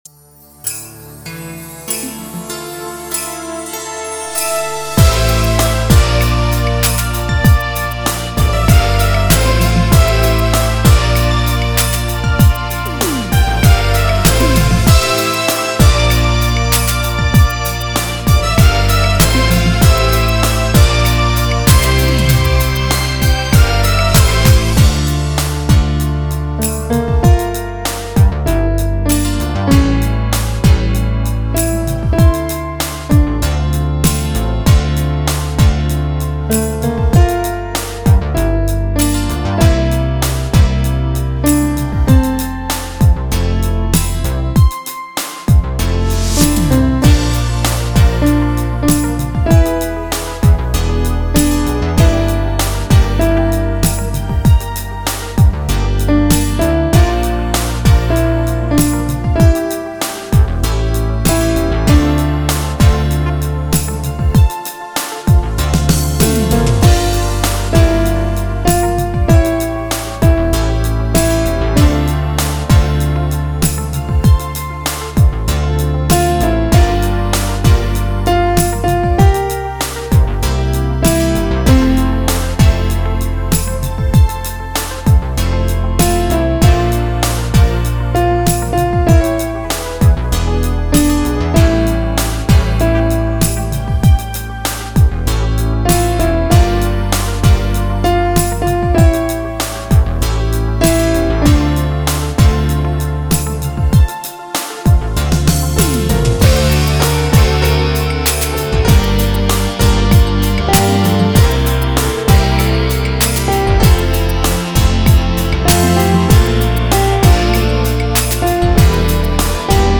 чуттєва і романтична! 16 Приємно розпочинати ранок гарними піснями